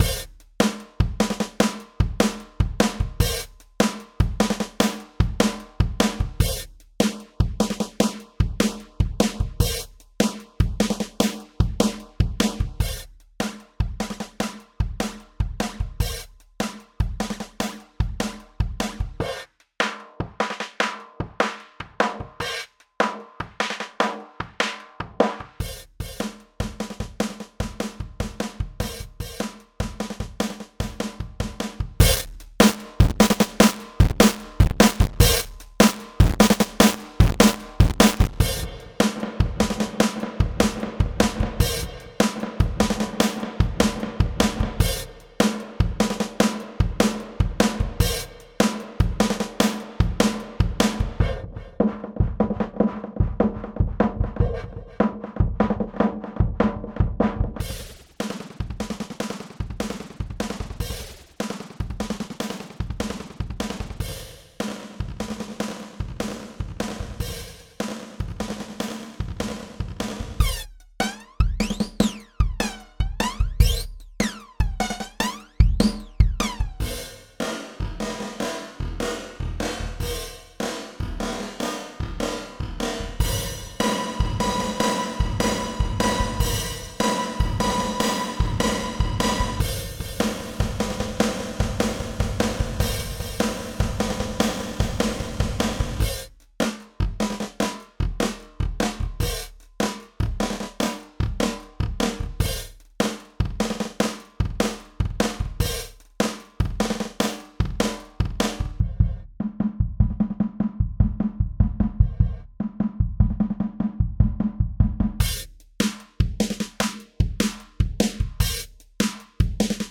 30 Popular Sound Effects in Music